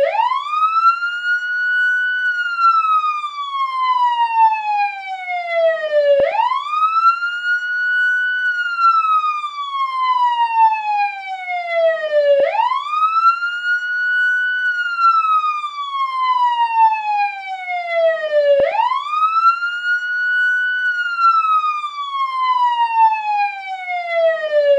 sirenin.wav